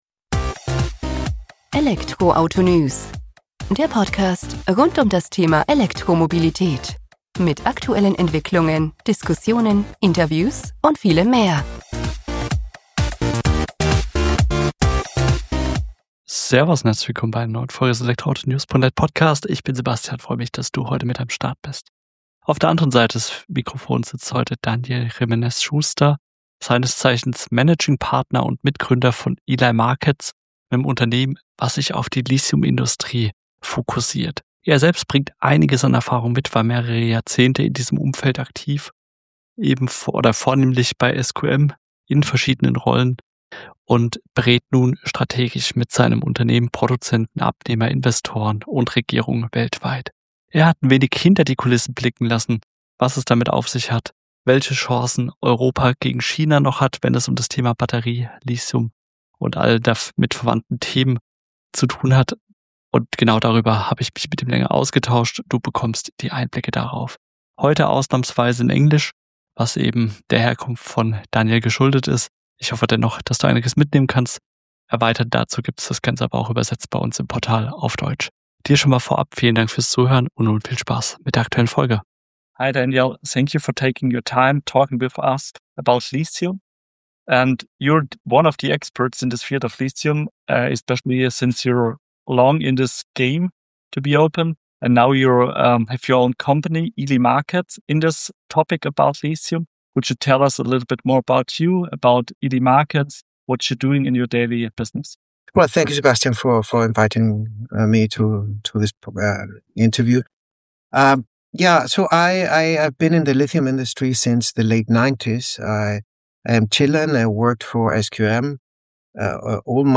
Beschreibung vor 3 Tagen In dieser Podcast-Folge spreche ich mit